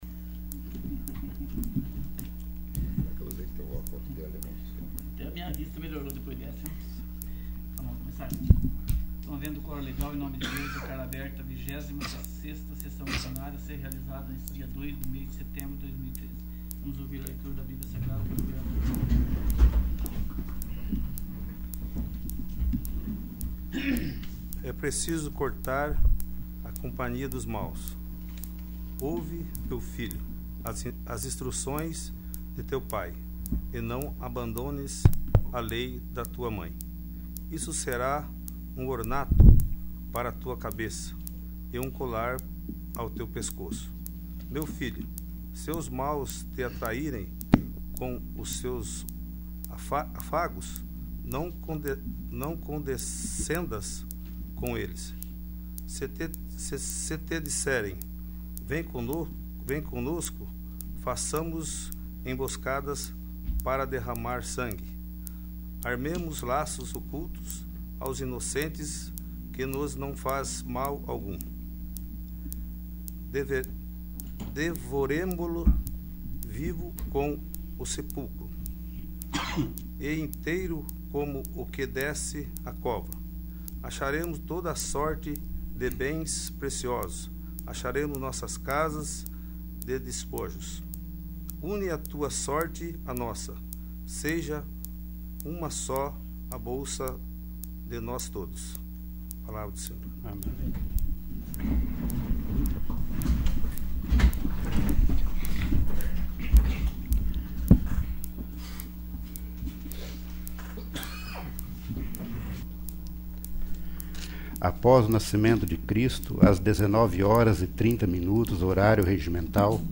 26º. Sessão Ordinária
| Ir para a navegação Ferramentas Pessoais Poder Legislativo Câmara de Vereadores do Município de Rio Bom - PR Mapa do Site Acessibilidade Contato VLibras Contraste Acessar Busca Busca Avançada…